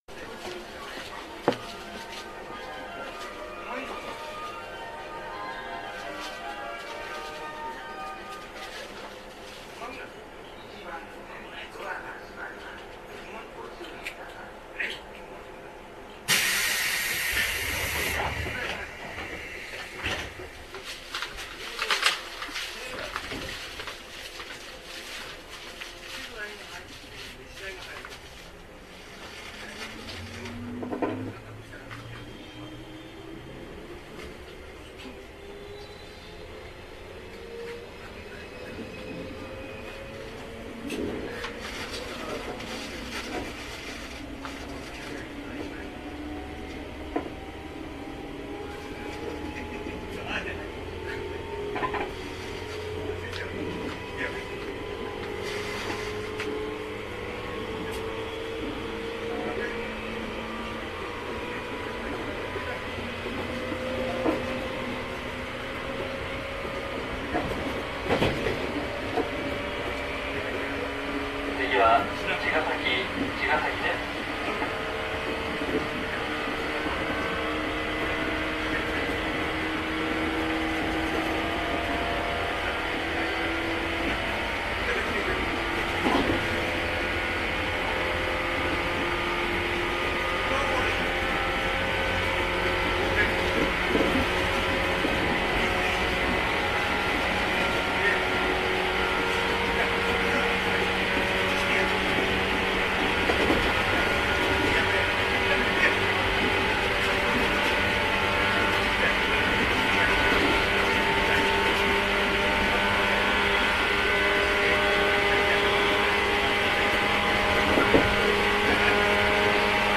特に高速時の力強いモーター音は何とも言えない迫力があります。
さてまずは、東海道線での迫力の走りをご堪能ください。